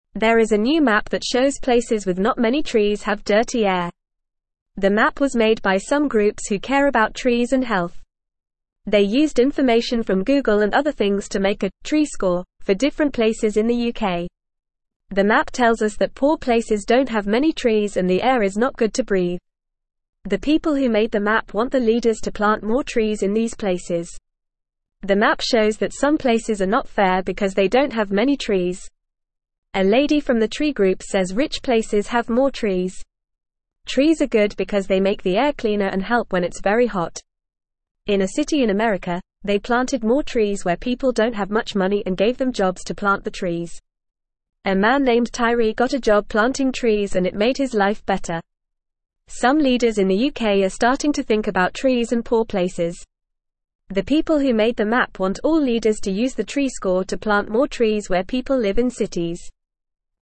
Fast
English-Newsroom-Beginner-FAST-Reading-Map-Shows-Places-with-Few-Trees-Have-Dirty-Air.mp3